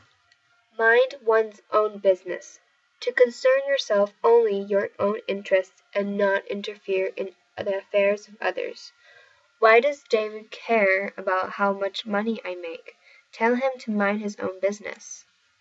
英語ネイティブによる発音は以下をクリックしてください。